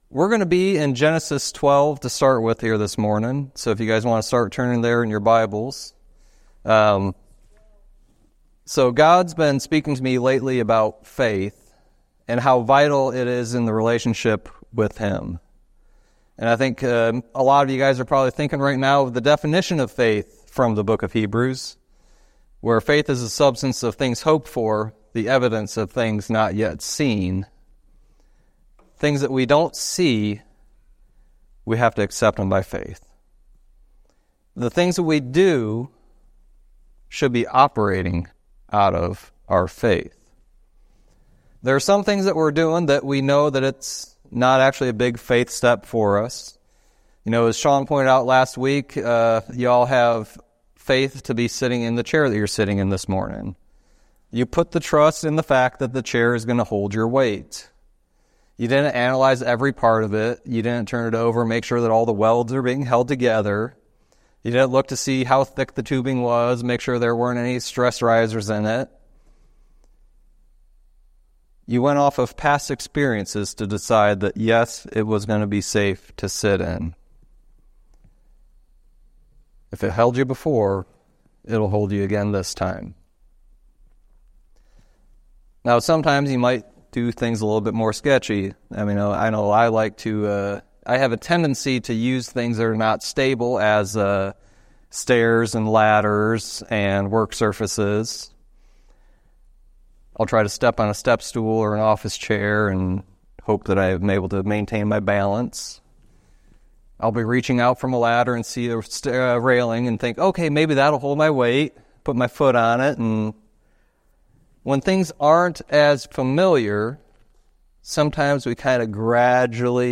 Jeremiah 29:4-14 Service Type: Sunday Teaching It’s important to have faith in everything we do